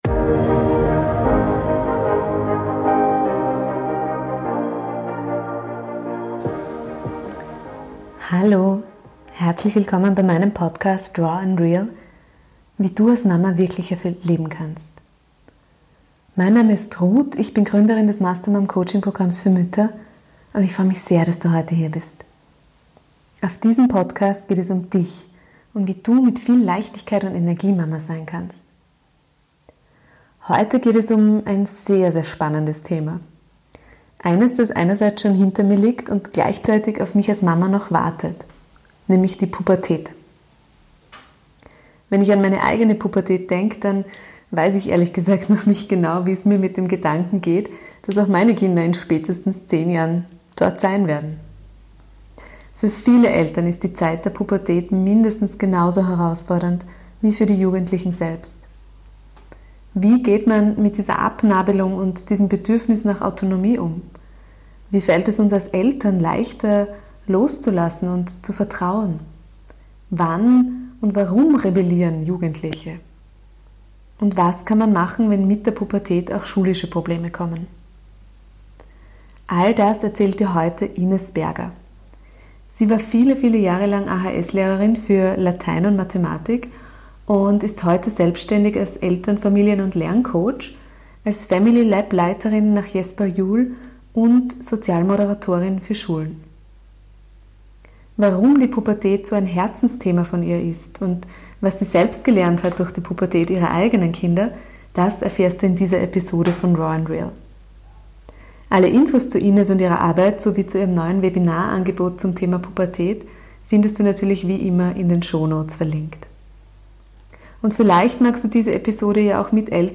#56 Wie wir als Eltern gut durch die Pubertät kommen. Interview